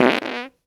pgs/Assets/Audio/Comedy_Cartoon/fart_squirt_17.WAV at master
fart_squirt_17.WAV